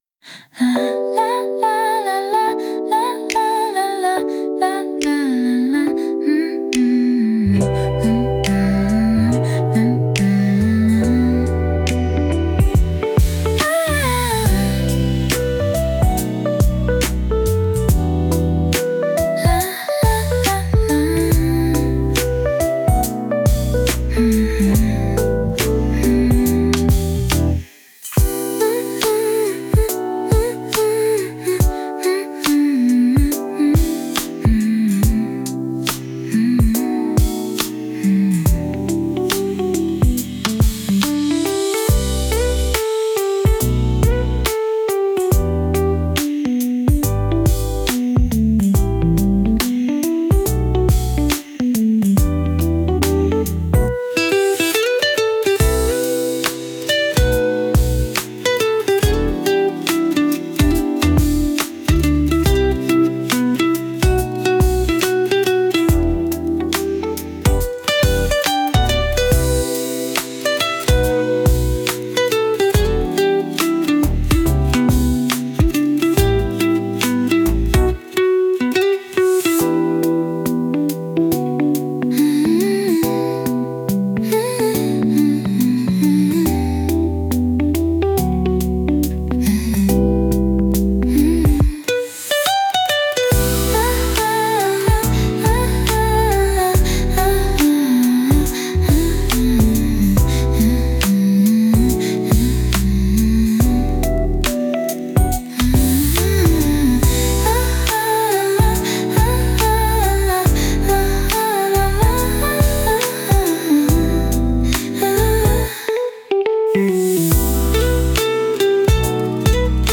With Vocals / 歌あり
楽器の音色とは一味違う、人の声による「ハミング」が奏でる、柔らかく優しいバラード。
言葉のない歌声だからこそ、聴く人の心にじんわりと染み渡るような温かさがあります。